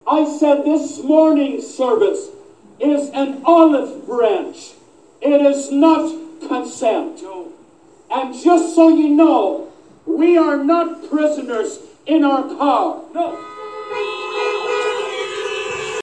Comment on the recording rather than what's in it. In what he called an act of conciliation, today’s service at Aylmer’s Church of God was of the drive-in variety.